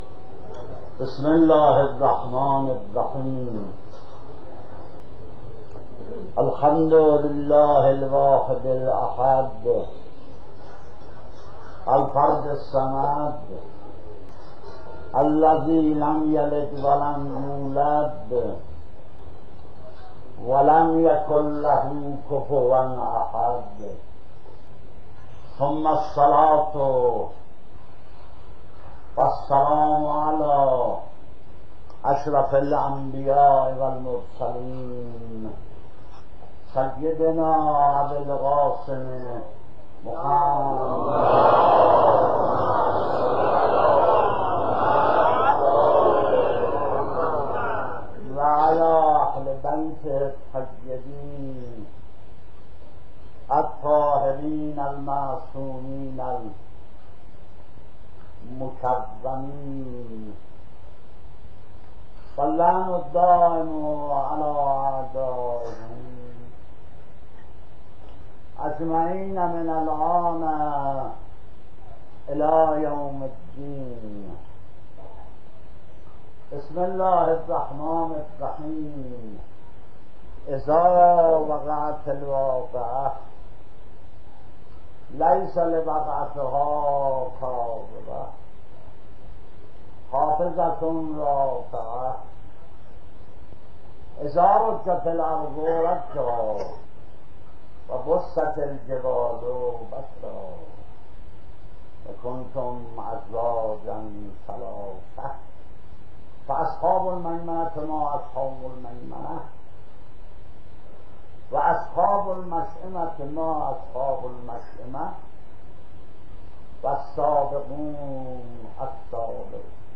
سخنرانی و روضه خوانی